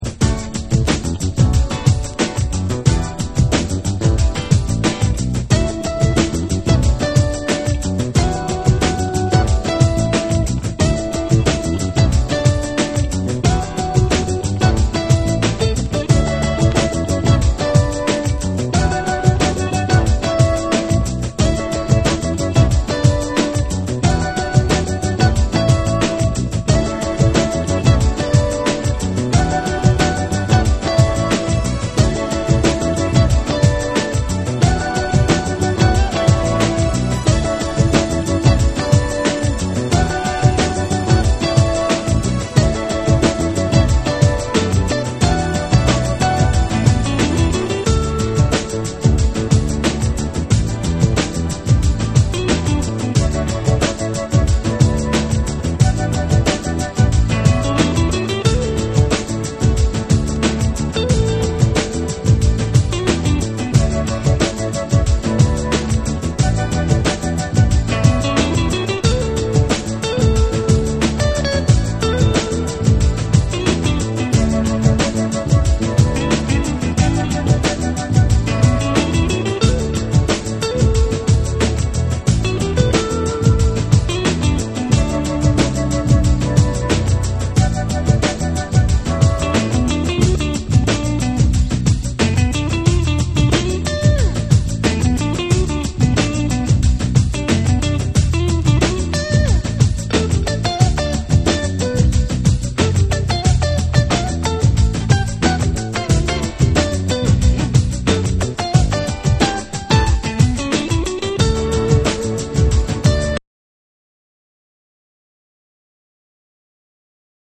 format : 12inch
BREAKBEATS